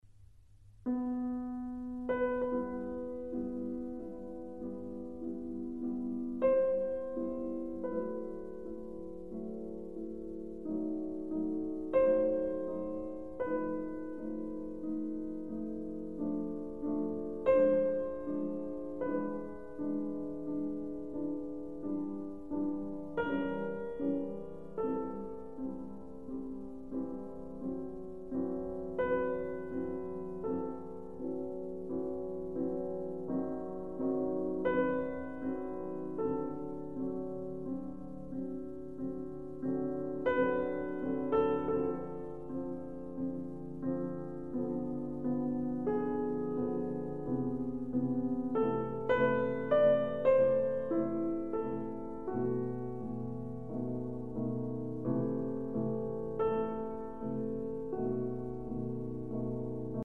Fryderyk Chopin - Prelude Op. 28 - No 4 in E minor Largo